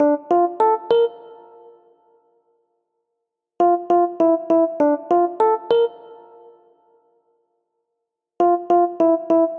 洗碗机(1分钟)
描述：标准洗碗机在连续循环上运行录制的Tascam Dr40立体声文件（16位Wav 44.1采样）
标签： 厨房 餐具 洗涤 一分钟 洗碗机
声道立体声